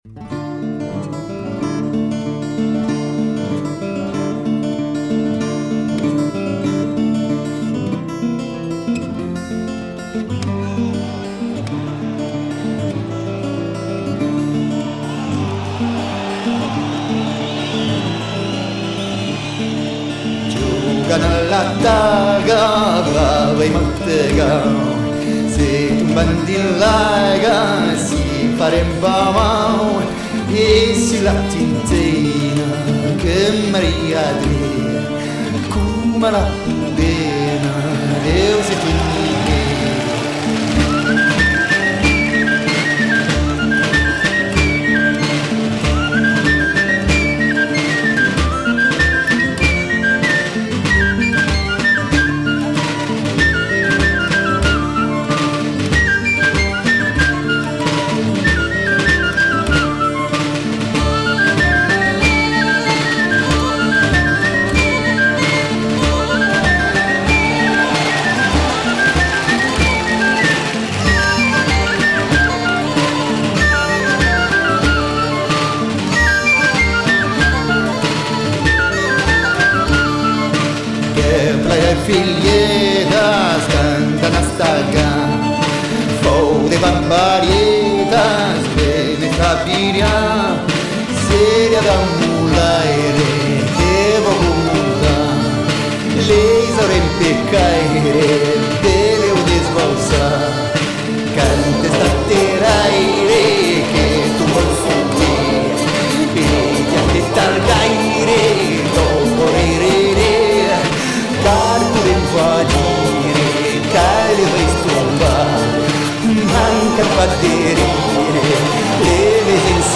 tradition de danses de caractère
Pièce musicale éditée